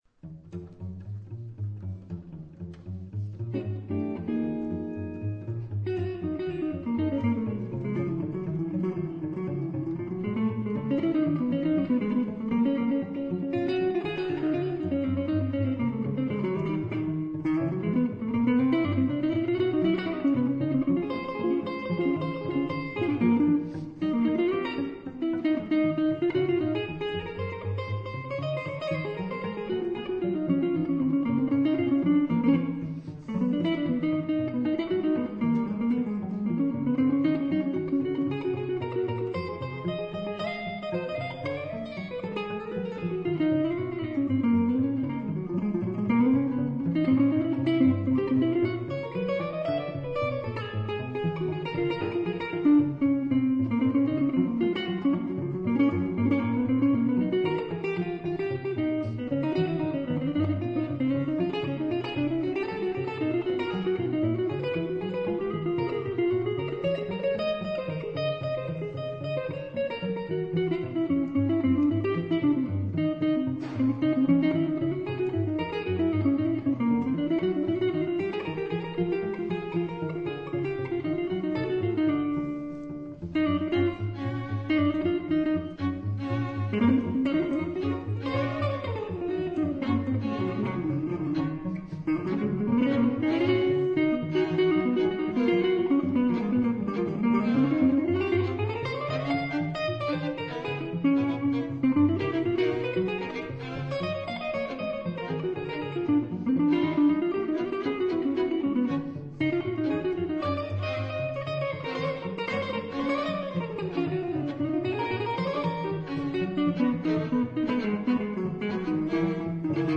Mixed bag of solo excerpts    [ top ]
jazz guitar